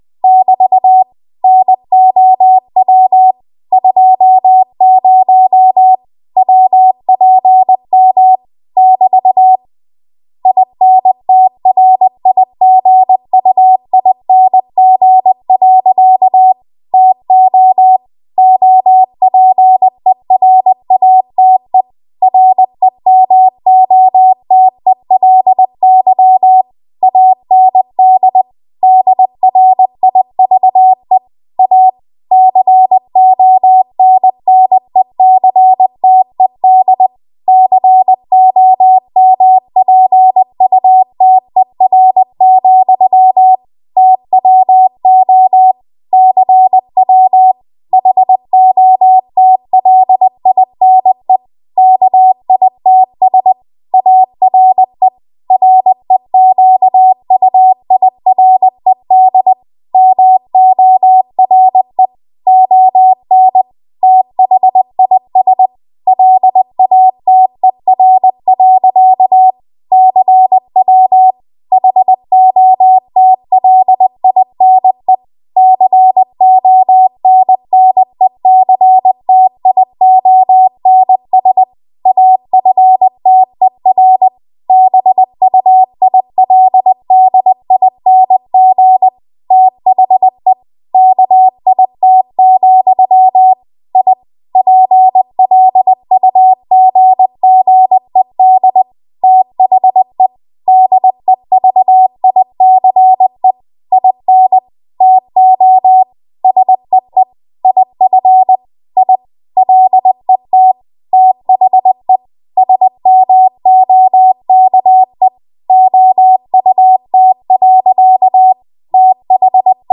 20 WPM Code Practice Archive Files
Listed here are archived 20 WPM W1AW code practice transmissions for the dates and speeds indicated.
You will hear these characters as regular Morse code prosigns or abbreviations.